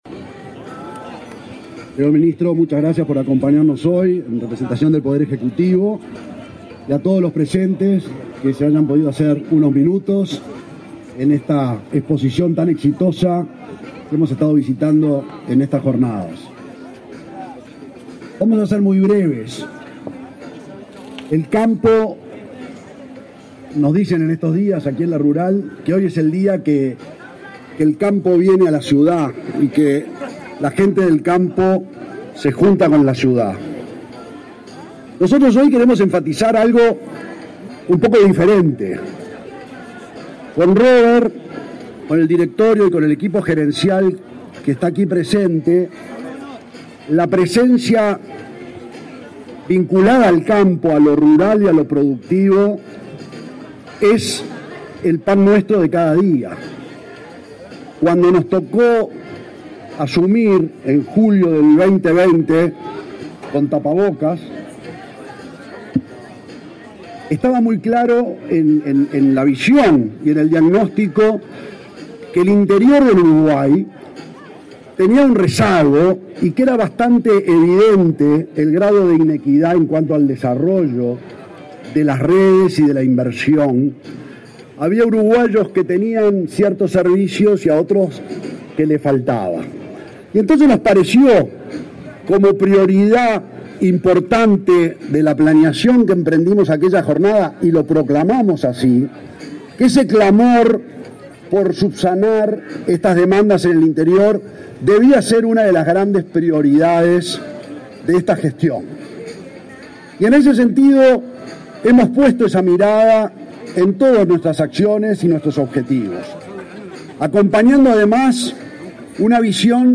Palabras de autoridades en inauguración de stand de Antel
Palabras de autoridades en inauguración de stand de Antel 13/09/2022 Compartir Facebook X Copiar enlace WhatsApp LinkedIn El presidente de Antel, Gabriel Gurméndez, y el ministro de Industria, Omar Paganini, encabezaron el acto de inauguración del stand del ente de las telecomunicaciones en la Expo Prado.